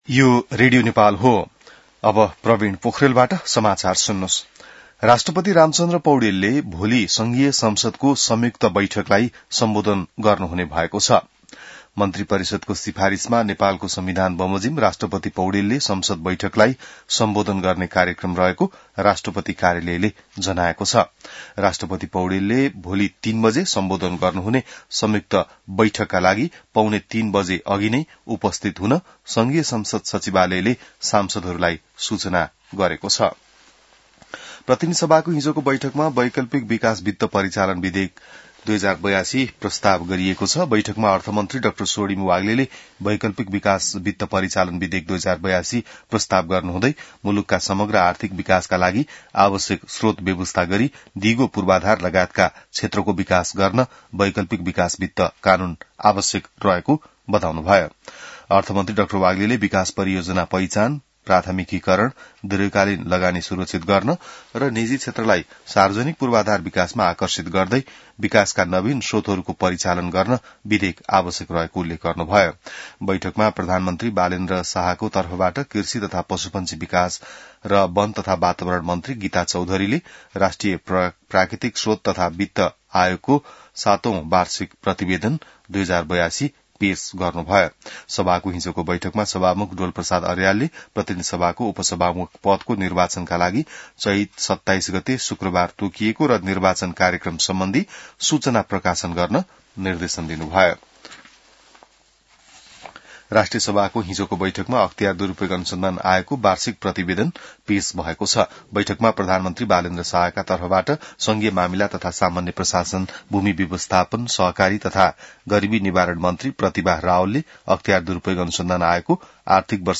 बिहान ६ बजेको नेपाली समाचार : २६ चैत , २०८२